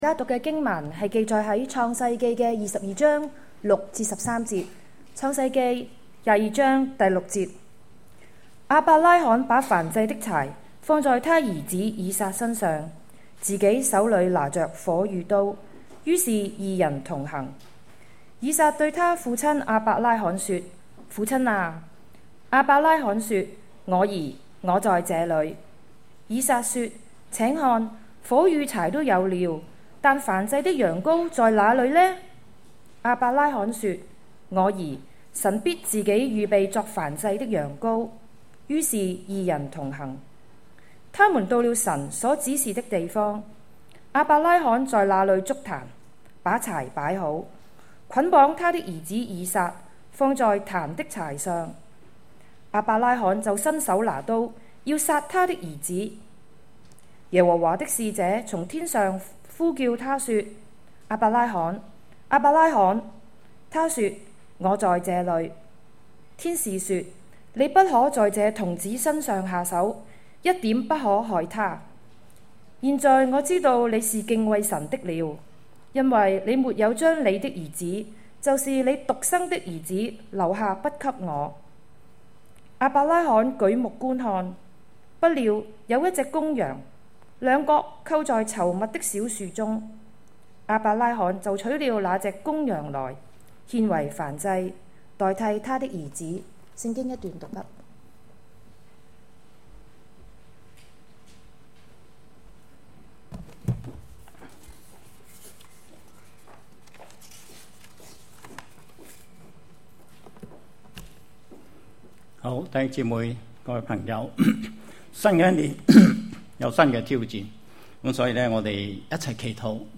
GracePoint Chinese Presbyterian Church 天恩華人長老會 - Service Recording